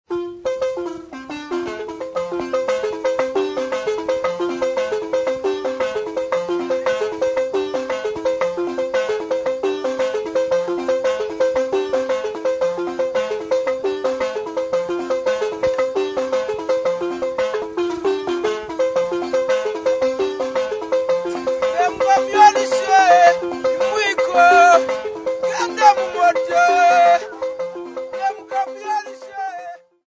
Lozi men
Tracey, Hugh
Folk music--Africa
Field recordings
sound recording-musical